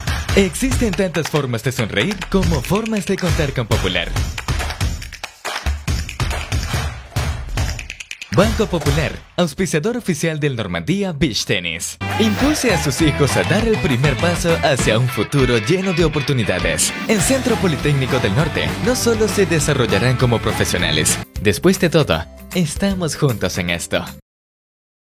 Español (América Latina)
Comercial, Joven, Natural, Travieso, Empresarial
Comercial